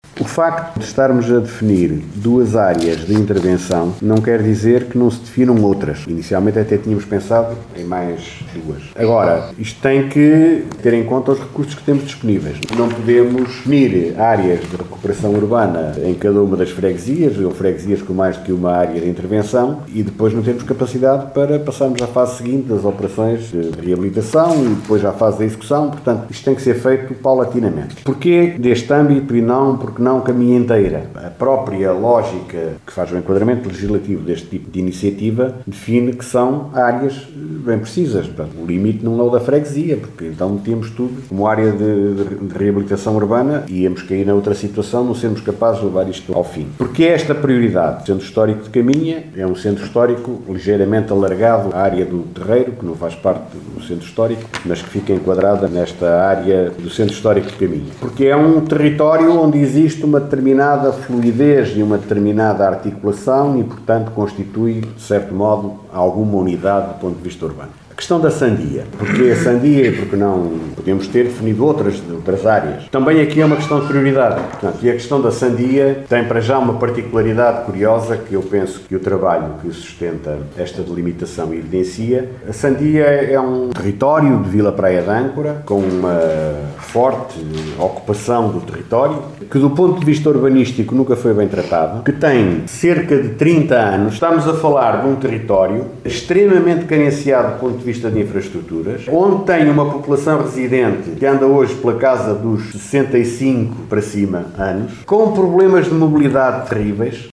O vereador socialista Guilherme Lagido, responsável pelo processo, explicou as razões que levaram à definição destas áreas prioritárias de reabilitação urbana e ressalvou que ainda não foram discutidas com os presidente de Juntas, mas que os autarcas já conhecem o processo: